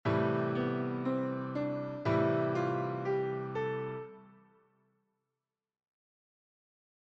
Gamme Mineure Naturelle
• En LA :
mineur_naturel_la.mp3